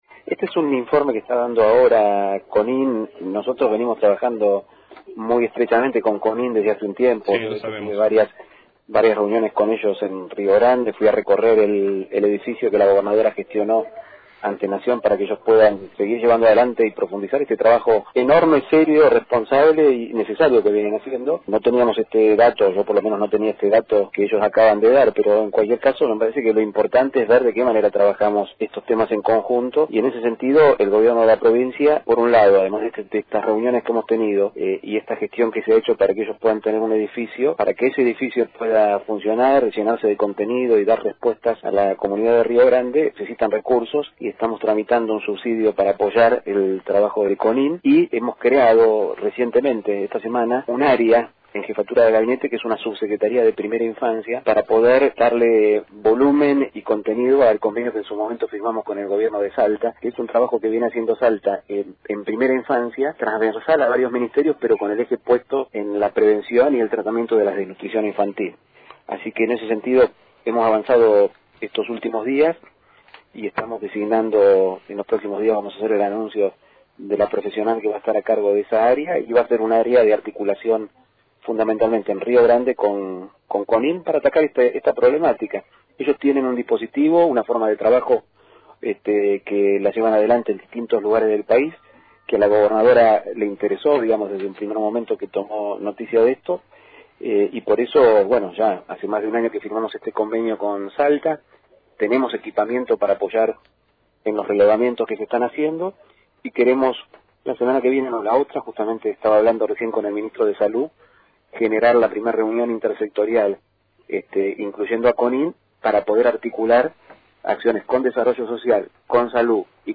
Los 30 casos de niños de hasta 3 años detectados en Río Grande no han sido analizados todavía por los funcionarios provinciales, pero serán motivo de trabajo conjunto y coordinado entre la Fundación Conín y las diversas áreas del gobierno, según afirmó el jefe de Gabinete en diálogo exclusivo con ((La 97)) Radio Fueguina.